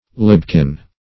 Search Result for " libkin" : The Collaborative International Dictionary of English v.0.48: Libken \Lib"ken\ (l[i^]b"k[e^]n), Libkin \Lib"kin\ (l[i^]b"k[i^]n), n. [AS. libban, E. live, v. i. + -kin.] A house or lodging.